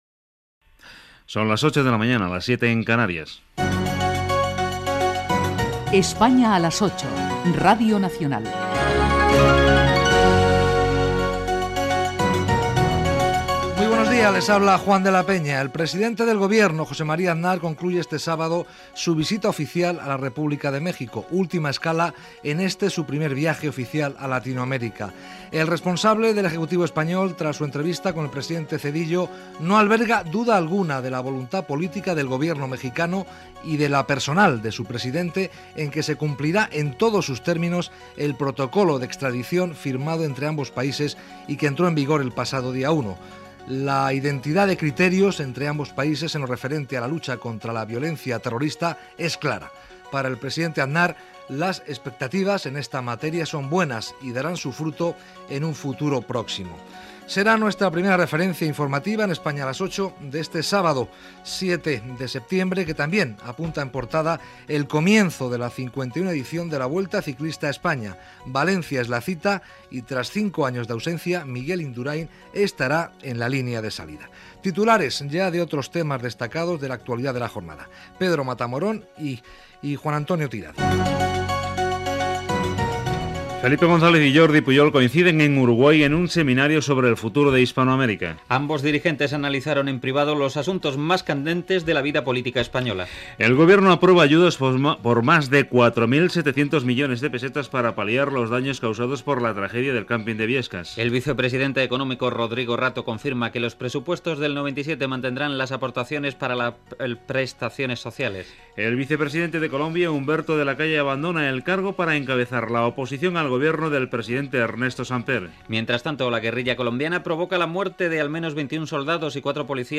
Hora, indicatiu del programa, visita del president espanyol José María Aznar a Mèxic, resum informatiu, hora, connexió amb Mèxic, reunió de Jordi Pujol i Felipe González a Montevideo, indicatiu
Informatiu